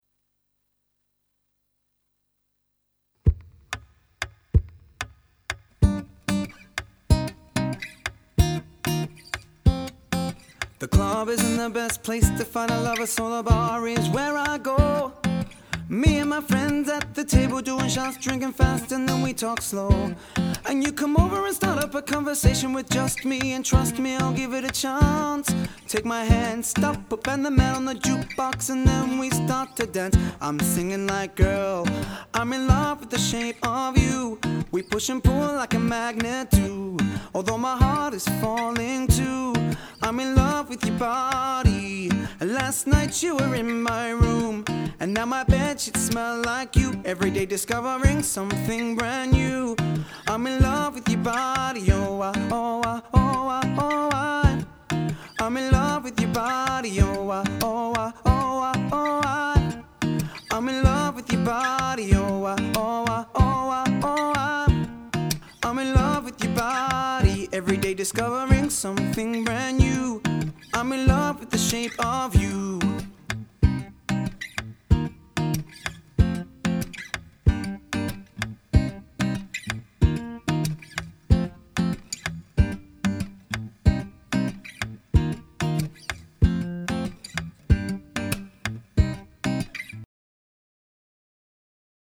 Vocals | Guitar | Looping | DJ | MC